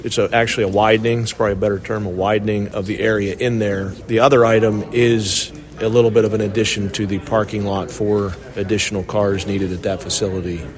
Board President Gregg Sacco said the two projects at Rayne Elementary are separate issues.